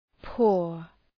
Προφορά
{pʋər}